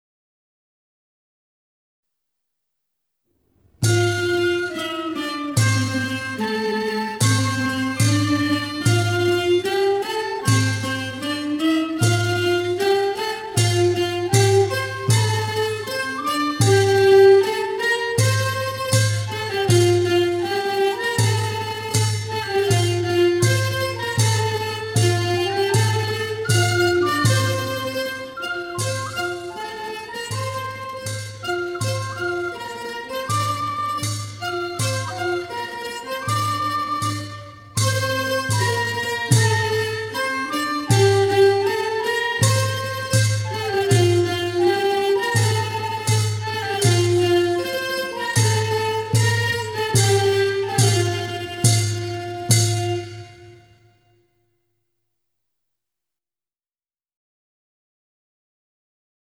วงเครื่องสาย